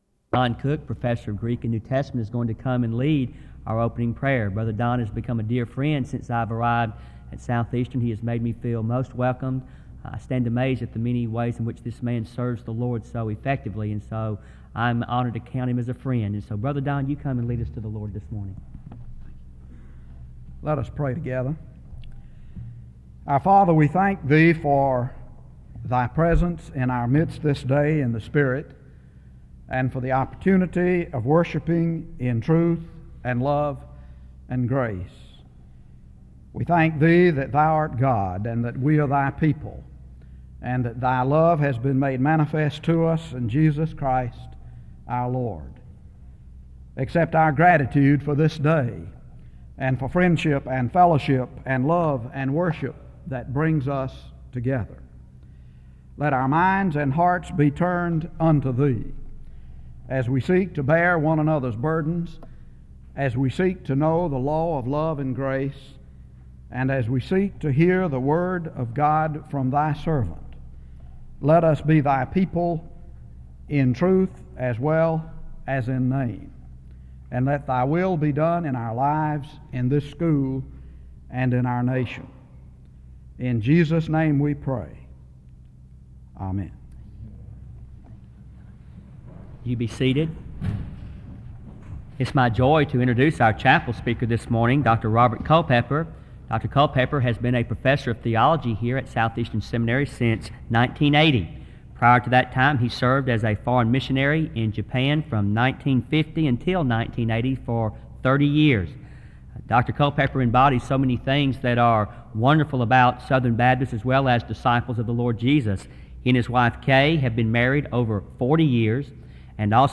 In Collection: SEBTS Chapel and Special Event Recordings SEBTS Chapel and Special Event Recordings